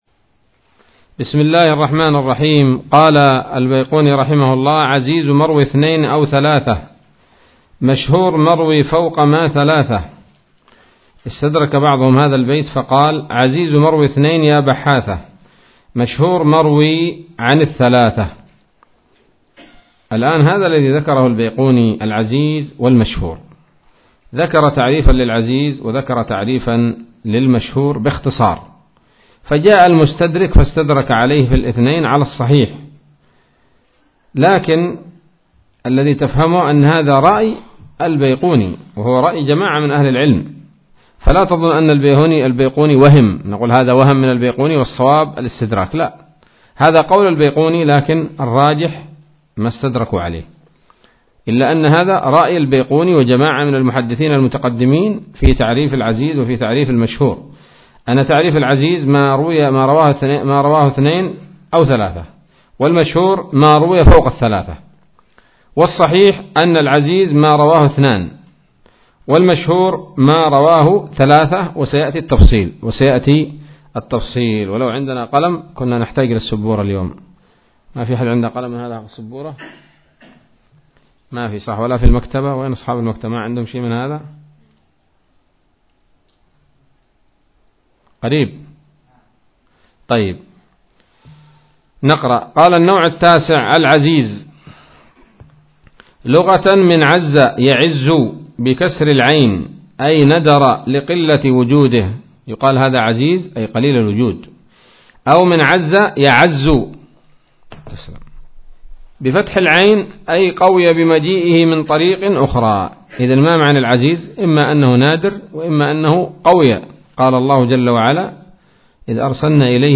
الدرس الخامس عشر من الفتوحات القيومية في شرح البيقونية [1444هـ]